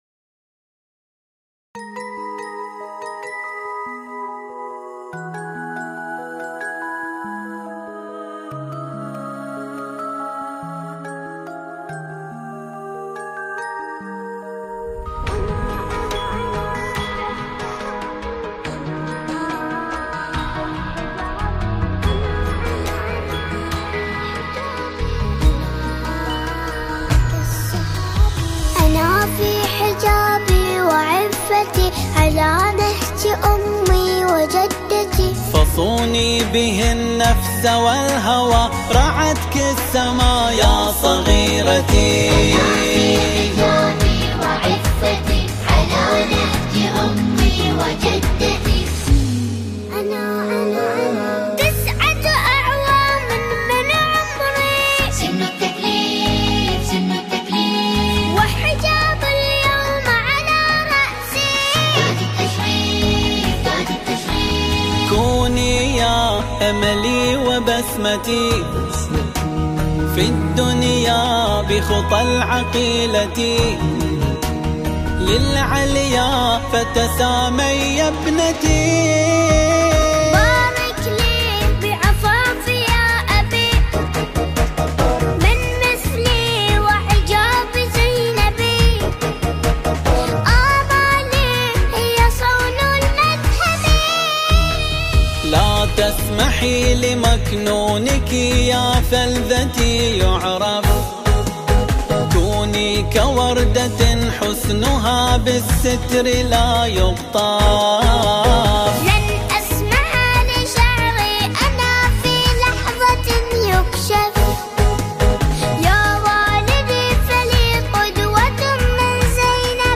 ألحان وكلمات وأداء
بمشاركة الطفلة:
تنفيذ Ai